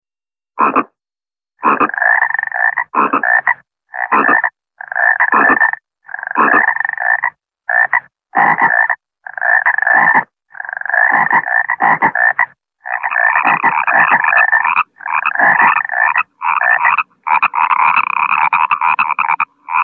. SMS hangok .
Békák
Brekegés
Frogs.mp3